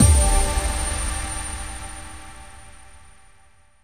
UI sparkle SFX